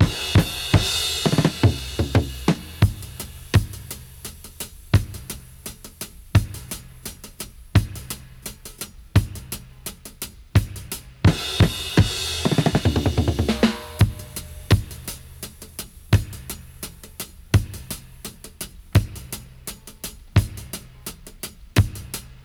85-FX-03.wav